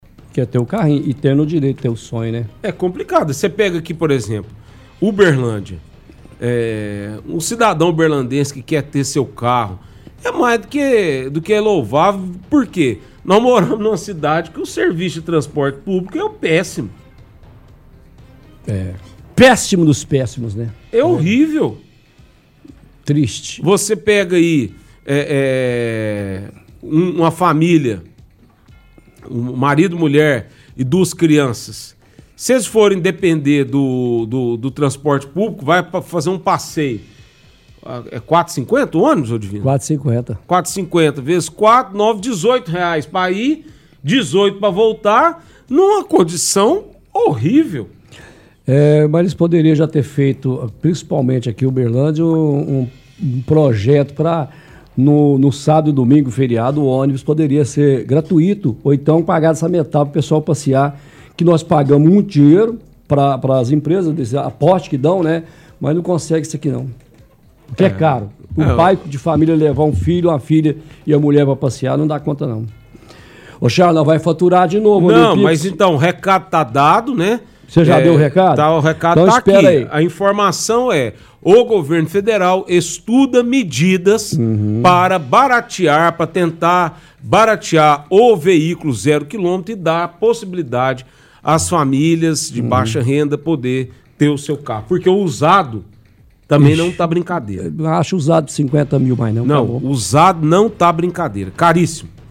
– Apresentadores estão discutindo alto preço de carros populares.